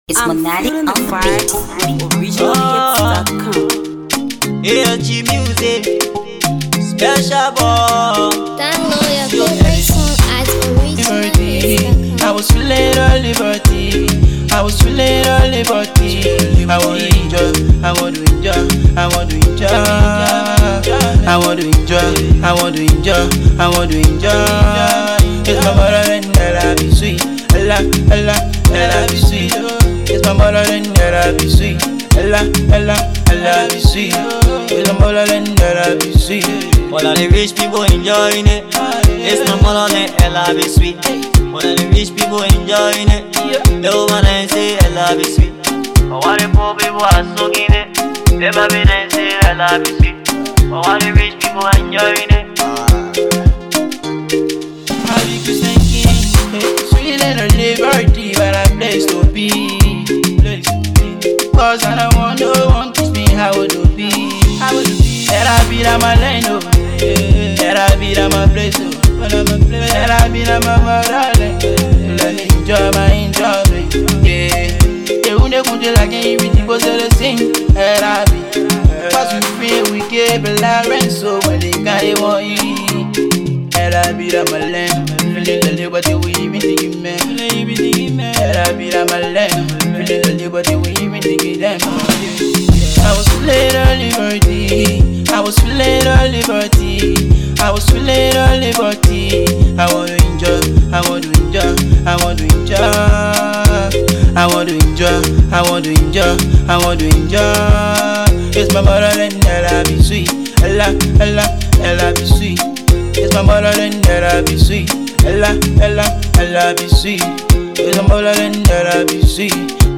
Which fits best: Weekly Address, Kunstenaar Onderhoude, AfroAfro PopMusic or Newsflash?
AfroAfro PopMusic